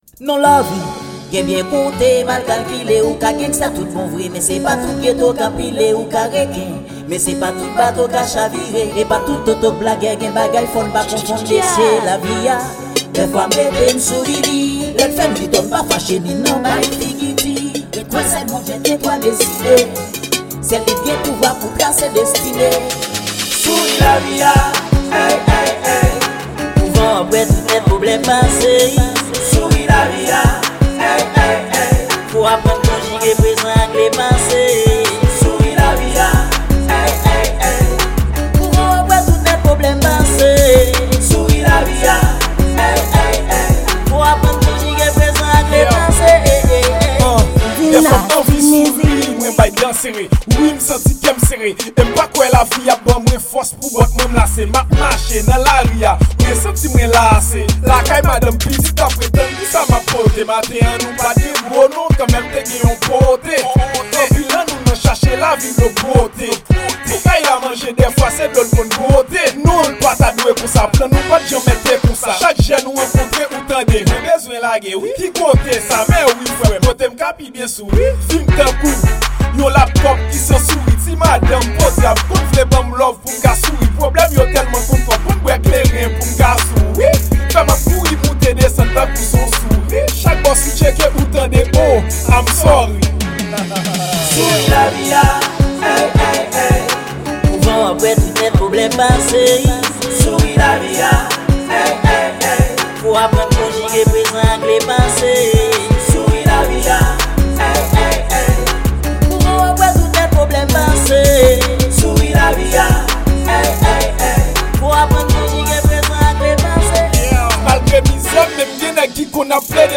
Genre: Rnb-Rap.